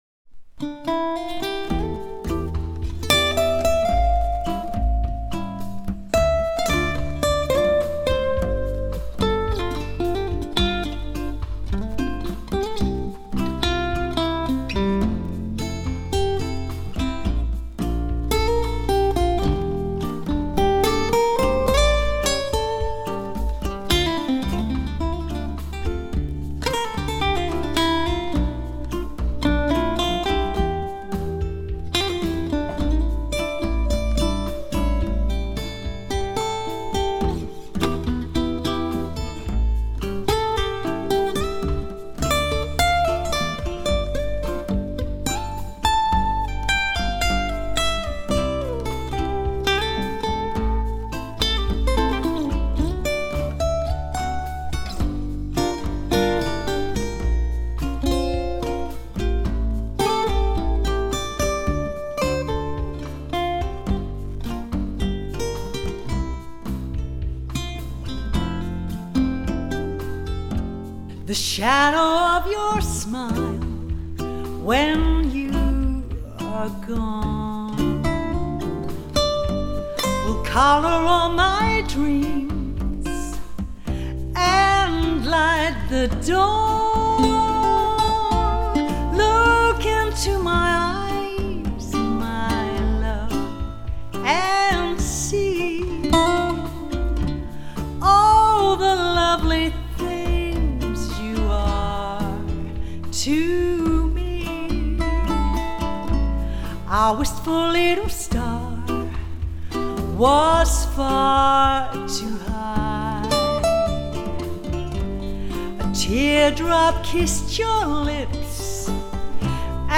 bass
The aim was to produce an acoustic bass with real bass frequencies.
I managed to get my target body frequency of 55hz. (most are 90-100hz.)
recording, just playing it into a Rode NT1a and rolling off some top end.